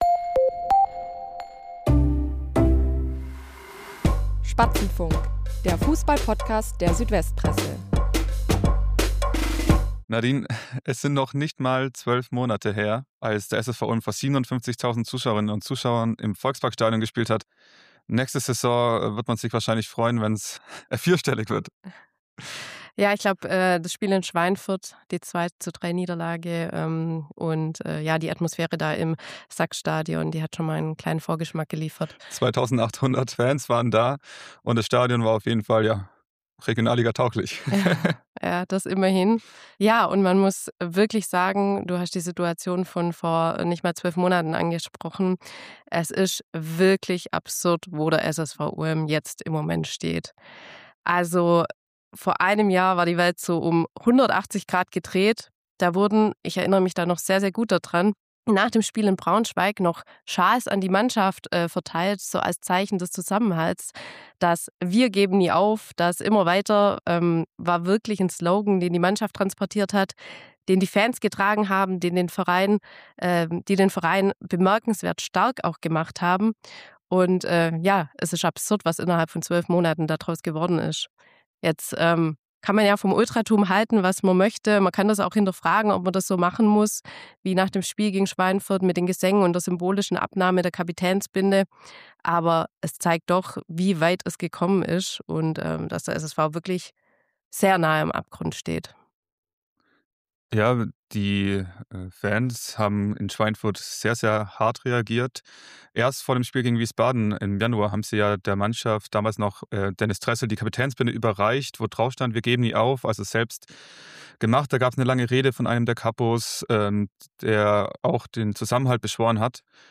Moderation